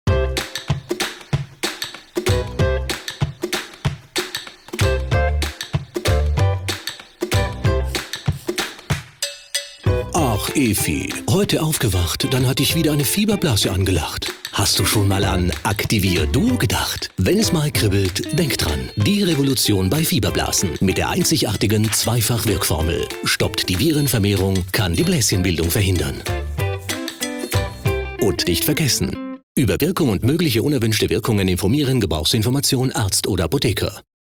Professioneller Sprecher mit markanter, warmer, sympathischer Stimme im besten Alter + Homestudio
Sprechprobe: Werbung (Muttersprache):